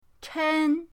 chen1.mp3